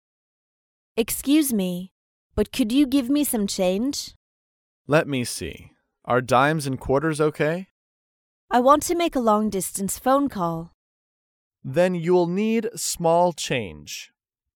dialogue
英语情景对话